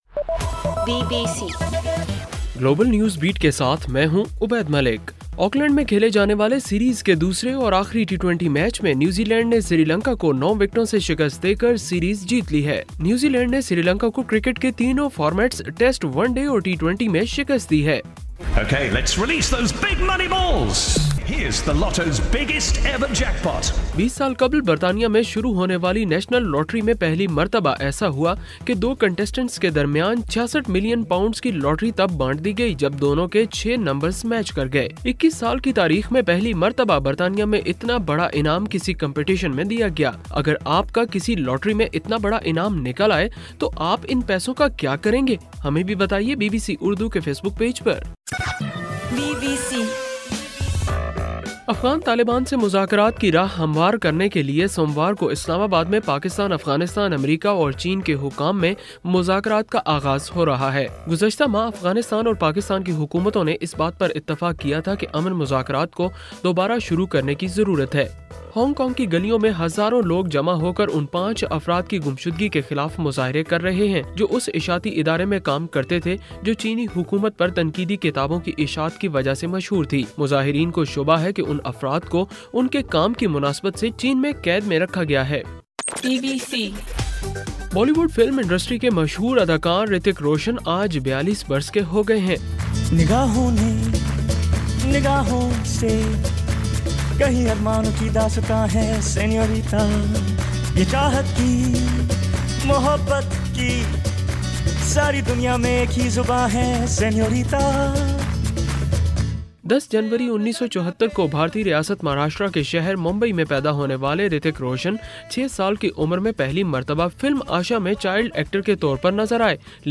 جنوری 10: رات 10 بجے کا گلوبل نیوز بیٹ بُلیٹن